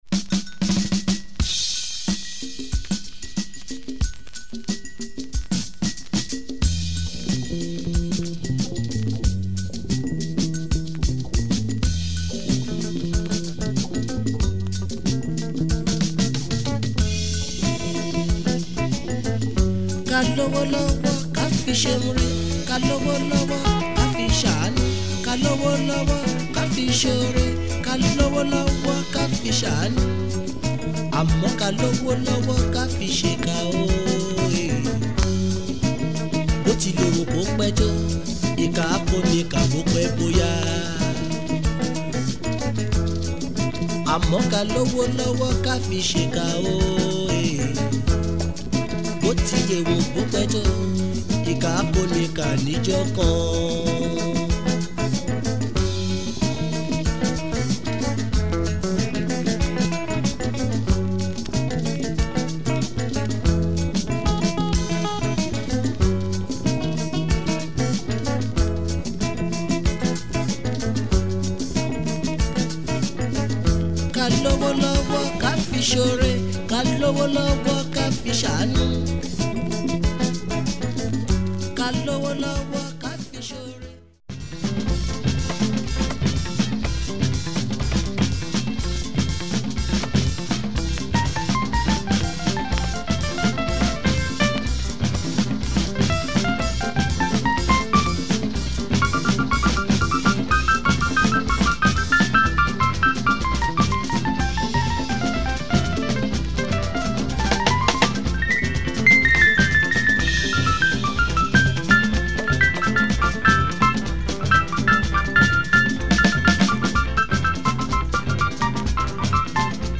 Very unusual soundtrack from Nigeria.